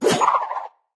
Media:Medic_base_atk_1.wav 攻击音效 atk 初级和经典及以上形态攻击或投掷治疗包音效